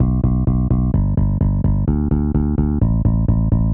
Tag: 128 bpm Electronic Loops Bass Synth Loops 970.43 KB wav Key : B